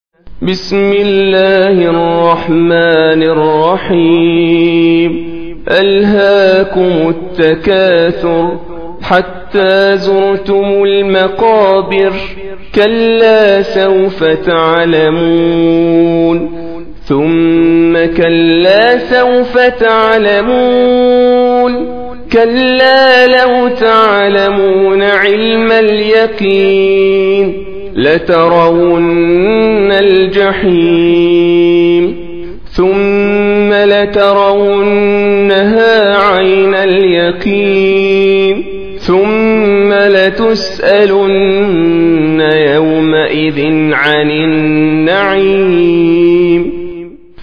Surah Sequence تتابع السورة Download Surah حمّل السورة Reciting Murattalah Audio for 102. Surah At-Tak�thur سورة التكاثر N.B *Surah Includes Al-Basmalah Reciters Sequents تتابع التلاوات Reciters Repeats تكرار التلاوات